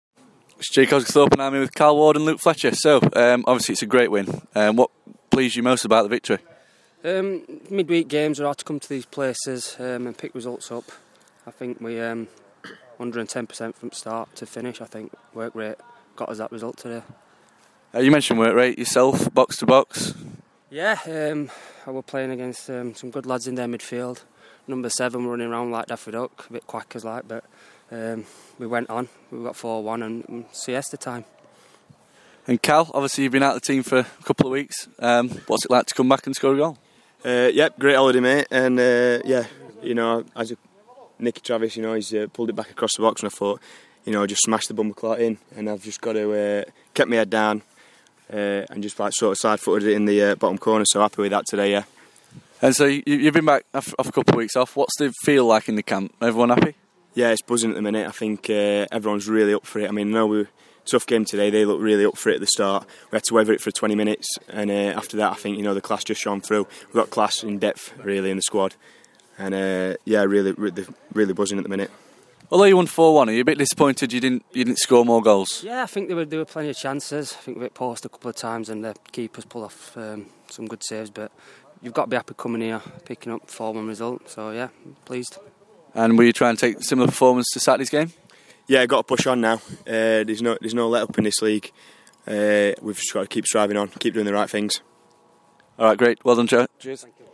Post match player interview